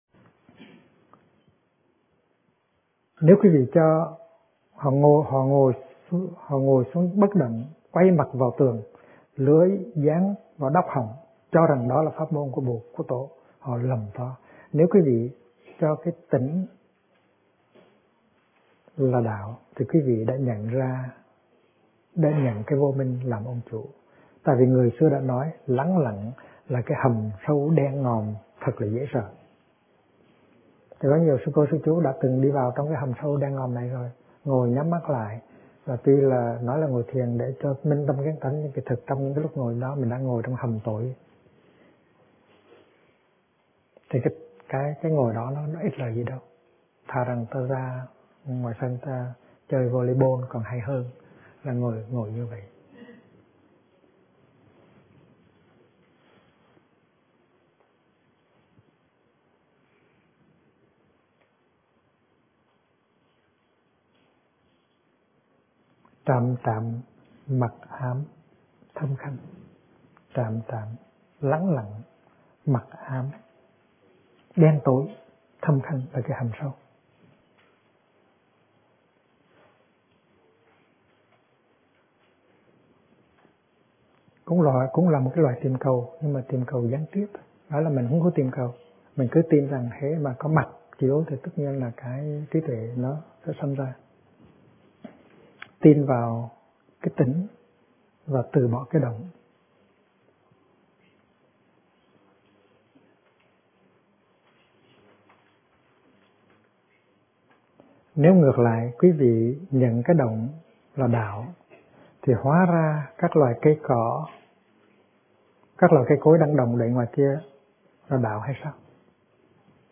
Kinh Giảng Ai Đang Đi Tìm Phật Pháp - Thích Nhất Hạnh